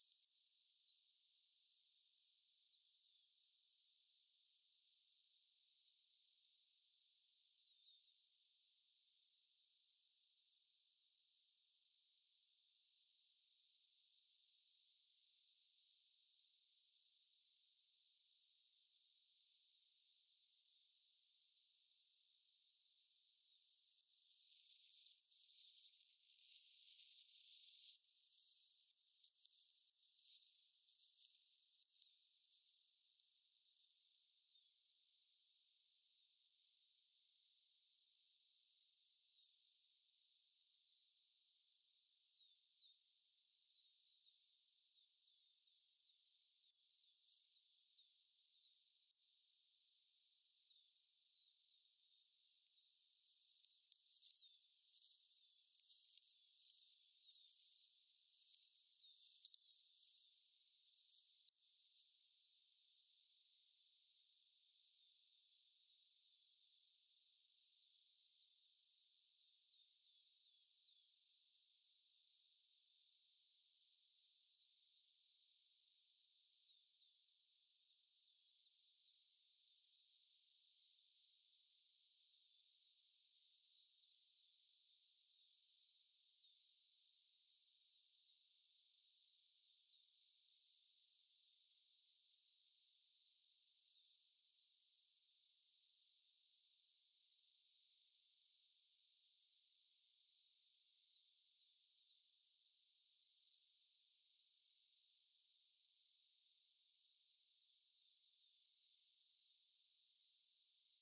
pyrstötiaisia saaressa
pyrstotiaisia_saaressa.mp3